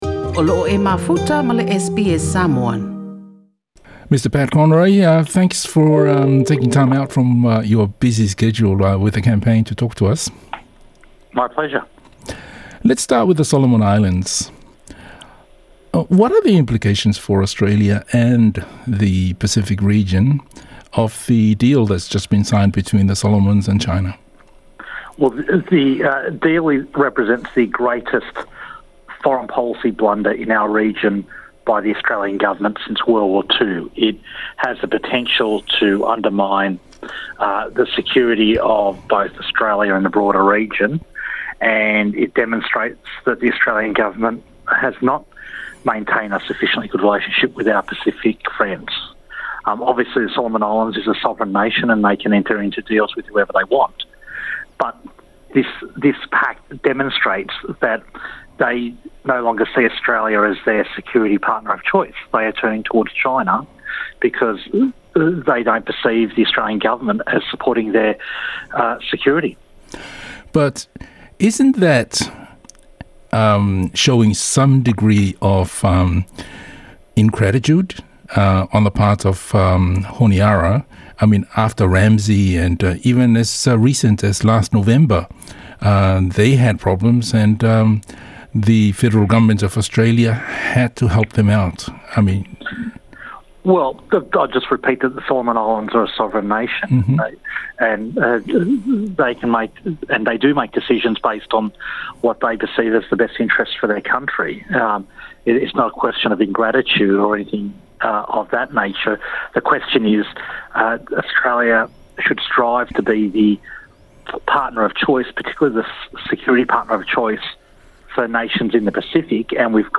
TALANOA: Pat Conroy - sui o le Labor mo mata'upu i le Pasefika.
Na maua se avanoa e talatalanoa ai ma le sui o le vaega 'upufai a le Labor i ausetalia (ALP) Pat Conroy, o ia le sui faaolioli o le ALP mo le tofi minisita o le mataupu i le Pasefika.